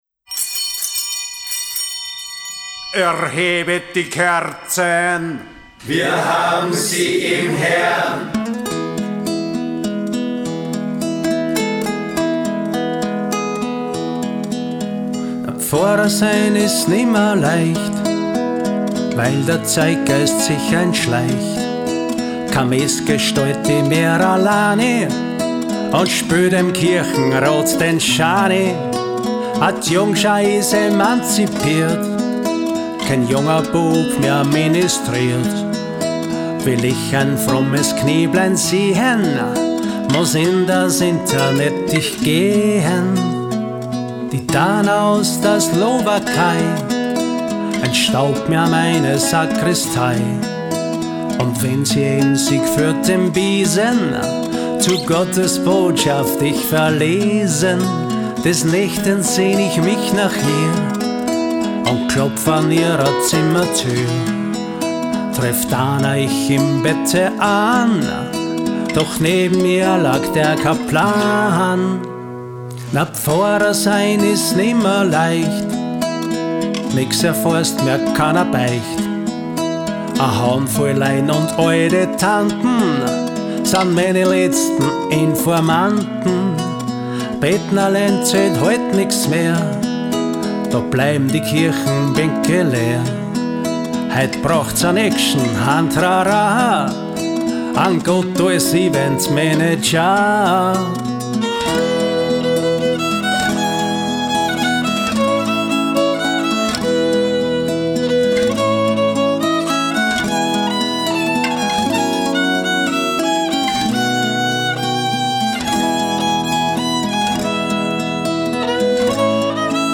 nyl-g